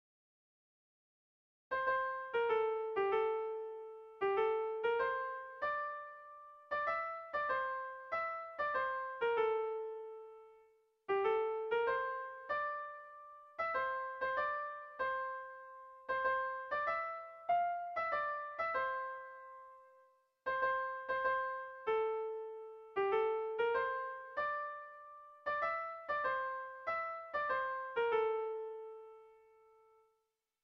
Oiartzun < Oarsoaldea < Gipuzkoa < Euskal Herria
ABA